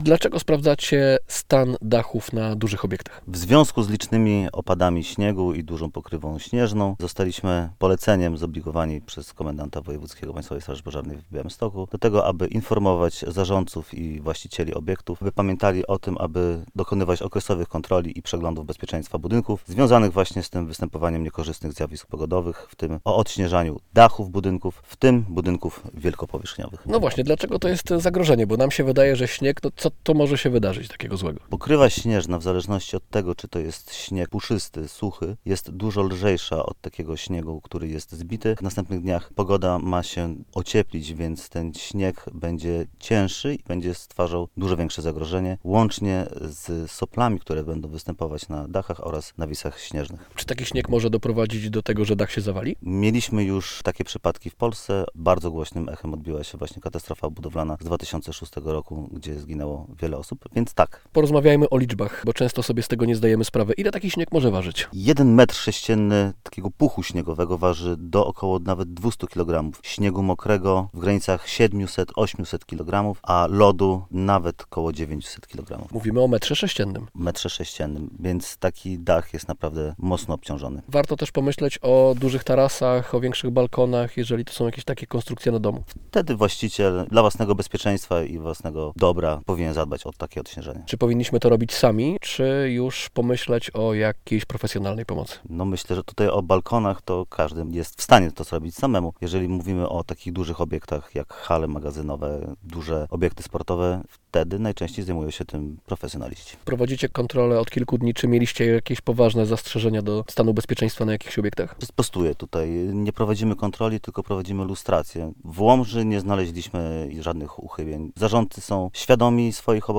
Ze starszym ogniomistrzem